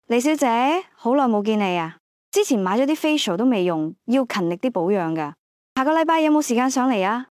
按此 : 收聽 項目例子 (3) _ AI 語音